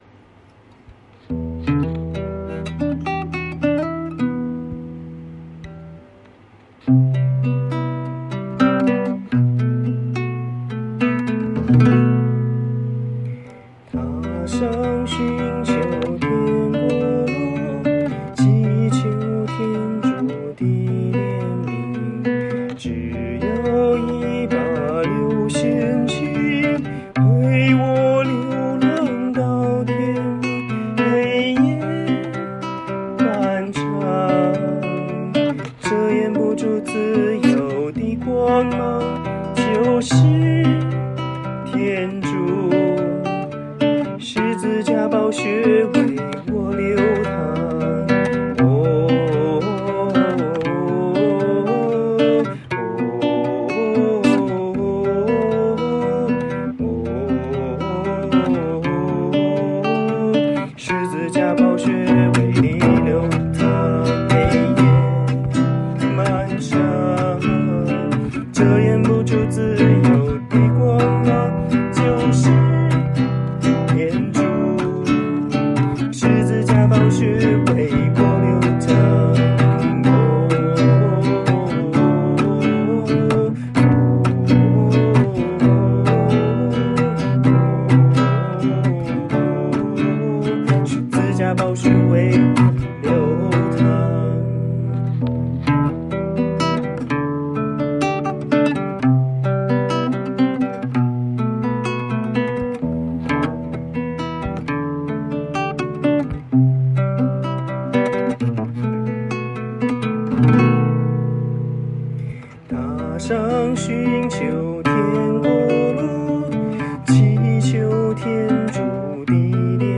用大提琴和爵士电吉他测试了人声部分，终于找到怪音的原因：多处用的颤音和推弦才能发出的音。